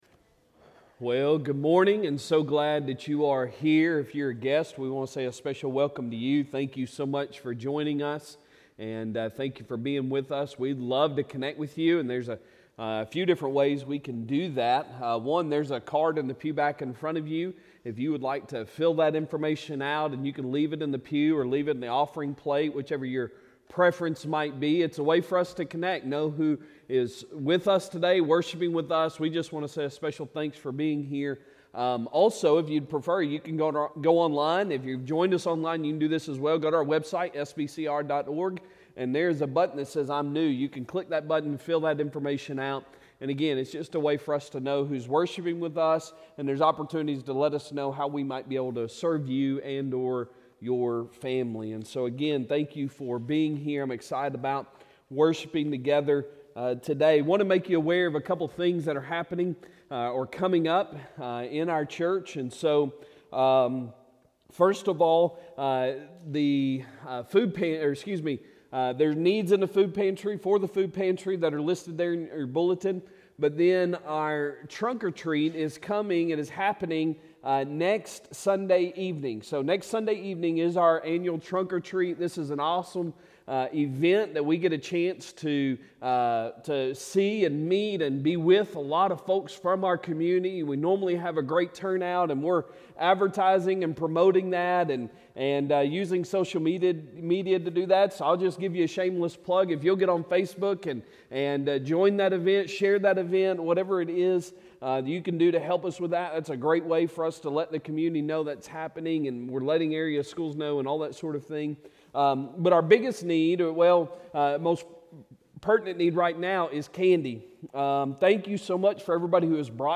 Sunday Sermon October 22, 2023